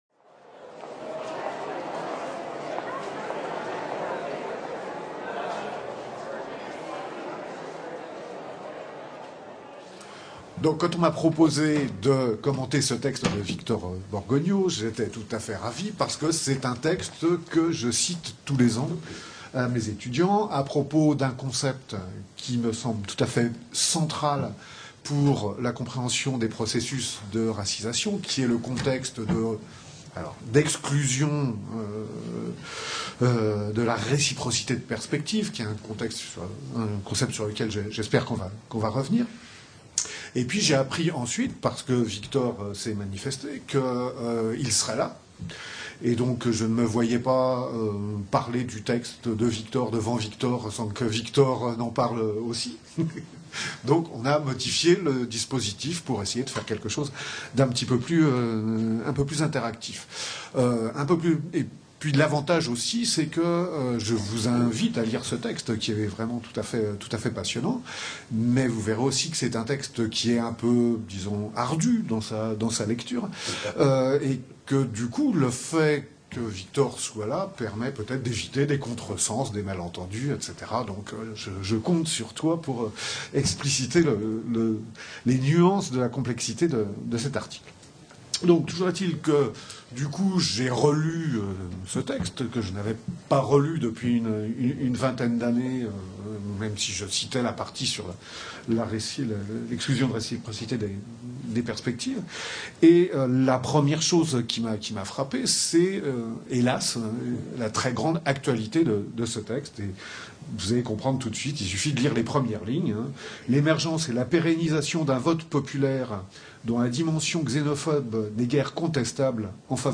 Colloque du cinquantenaire de la création du CERIN, Centre d’études des relations interethniques/ Ideric, Institut d’études et de recherches interethniques et interculturelles 3 et 4 mai 2017, MSHS, Université Nice Sophia Antipolis